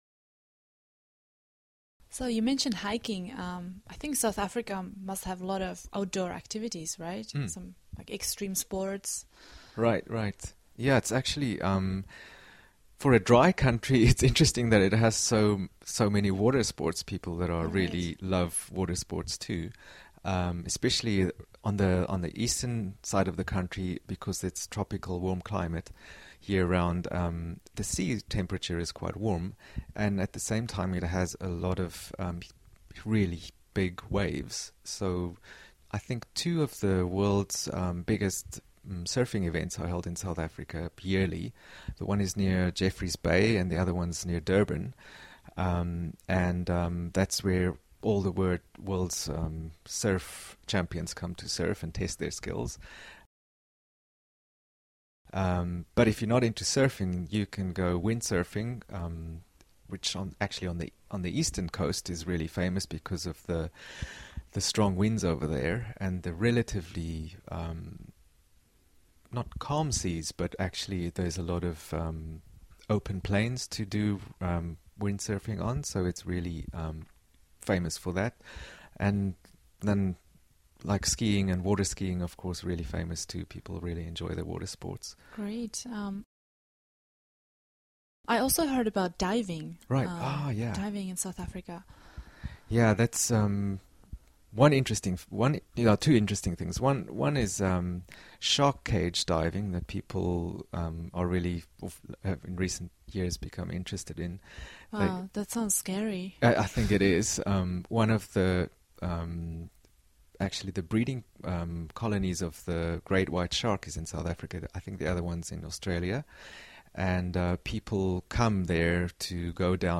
在线英语听力室英文原版对话1000个:1212 Water Adventures的听力文件下载,原版英语对话1000个,英语对话,美音英语对话-在线英语听力室